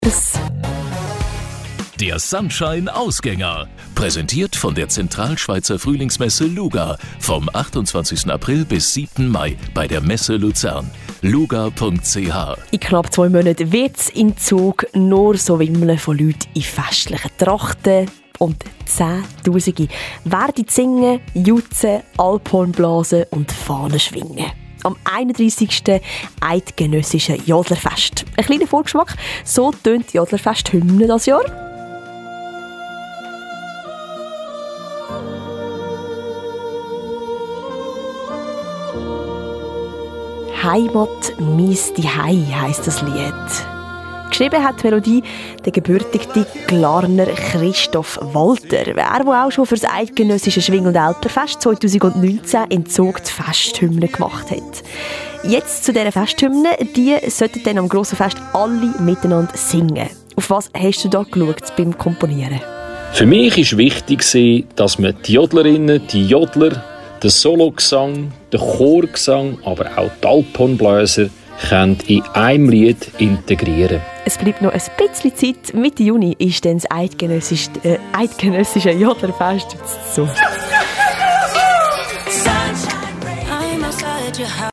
Report Sunshine Radio 04/23